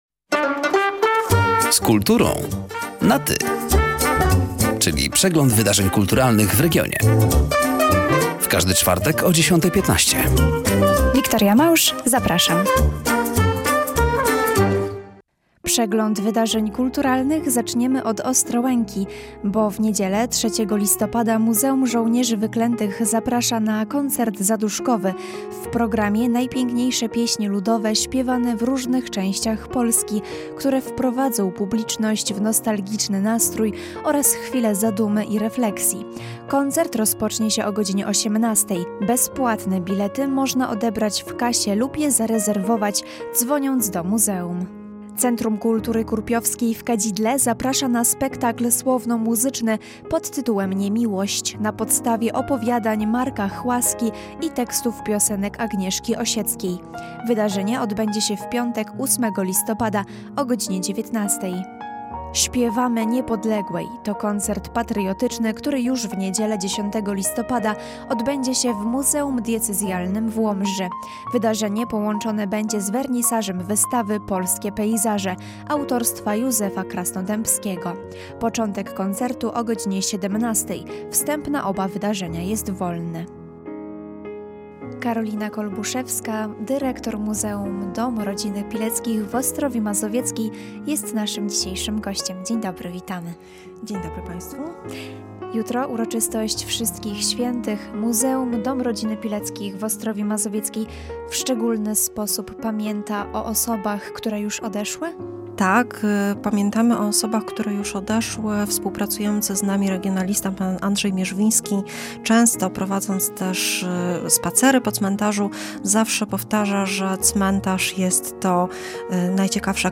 ,,Z kulturą na Ty” na antenie Radia Nadzieja w każdy czwartek o 10.15.